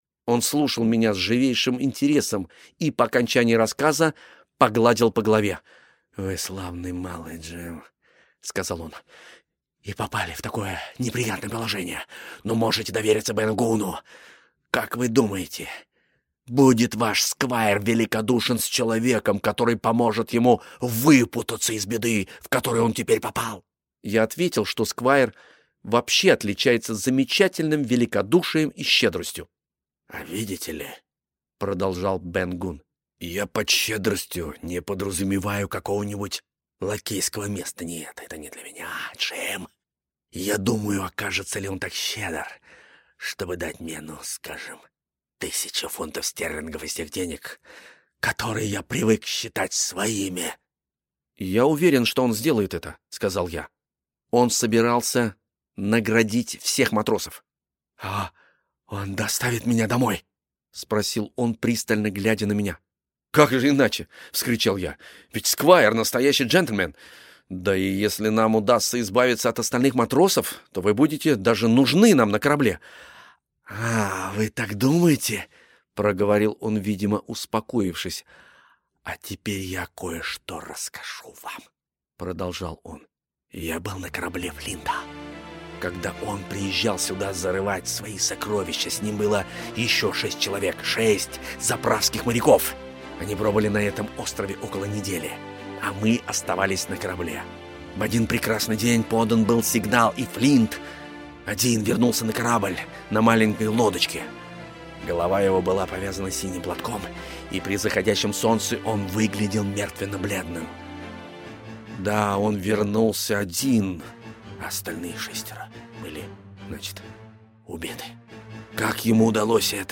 Аудиокнига Остров сокровищ | Библиотека аудиокниг
Aудиокнига Остров сокровищ Автор Роберт Льюис Стивенсон Читает аудиокнигу Алексей Веселкин.